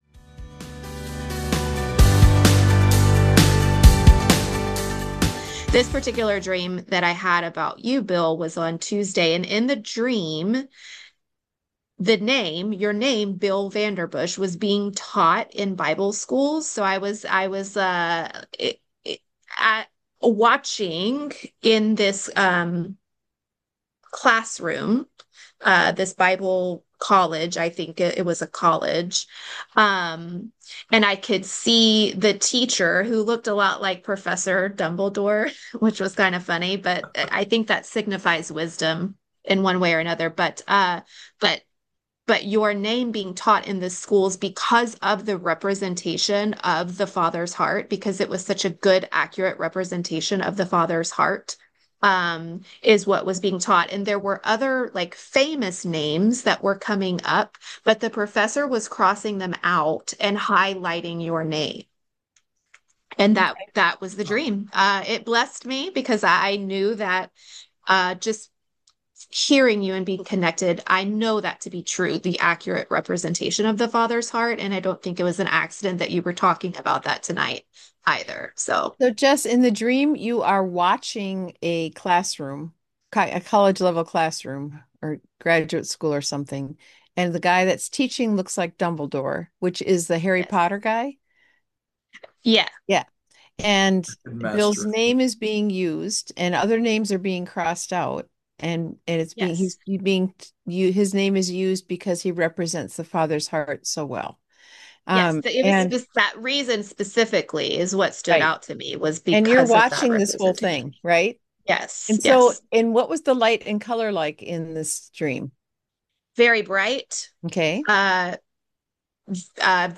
This conversation is a beautiful reminder that God delights […]